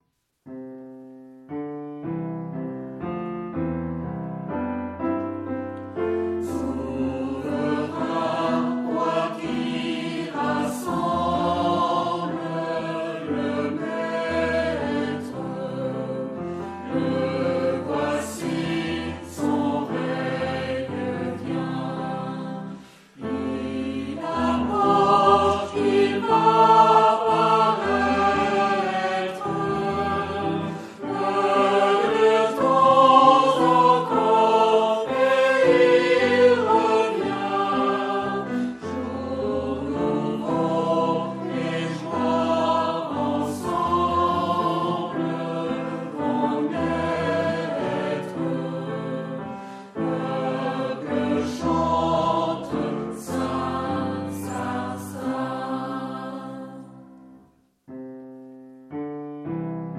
Genre-Style-Forme : Renaissance ; Sacré ; Hymne (sacré)
Type de choeur : B  (1 voix unisson )
Instruments : Orgue (1)
Tonalité : do majeur